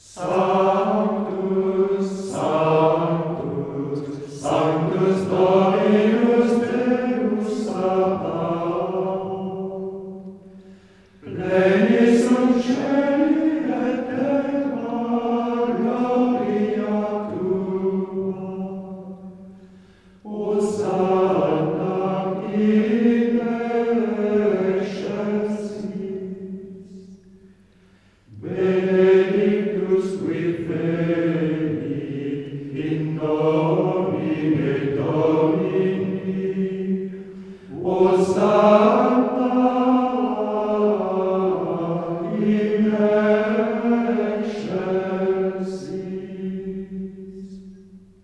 Il est pratiquement syllabique, à quelques exceptions près.
Nous sommes en présence d’un 8 mode, ferme, bien appuyé sur sa tonique Sol sur laquelle s’achèvent toutes les phrases musicales.
C’est un 8 mode, mais son caractère majoritairement syllabique en fait certainement un des Sanctus grégoriens les plus légers, les plus enthousiastes. En fait il est assez contrasté, passant de l’élan bien marqué à une période beaucoup plus intérieure.
Toute cette seconde phrase est parfaitement syllabique, très légère.
C’est donc un passage beaucoup plus doux, plus intérieur.
Voilà un Sanctus facile et expressif que la foule peut aisément apprendre et chanter.
Abbaye-Notre-Dame-dHautecombe-France-Sanctus-XIII-.mp3